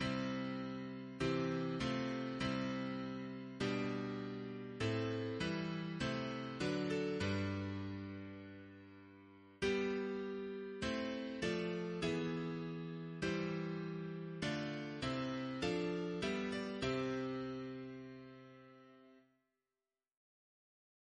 Double chant in C Composer: John Bertalot (b.1931), Organist of Blackburn Cathedral Note: paraphrase of St. Anne Reference psalters: ACP: 207